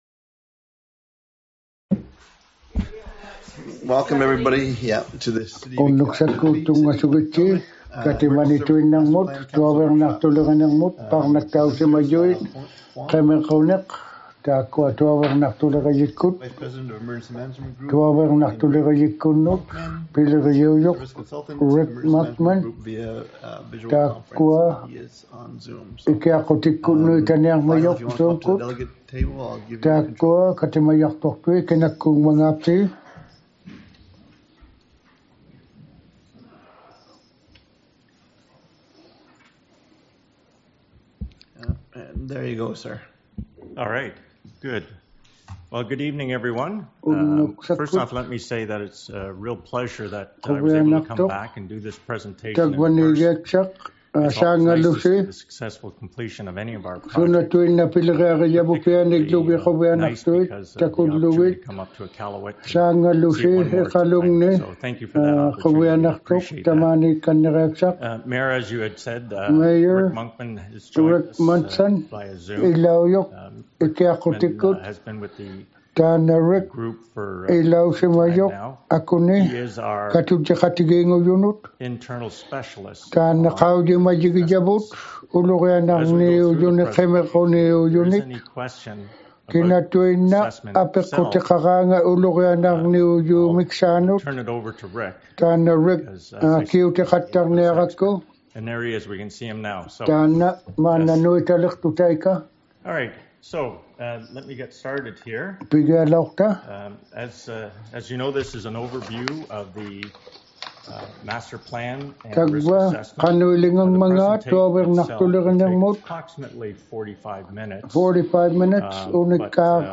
iqaluit_emergency_services_master_plan_council_workshop_inuk.mp3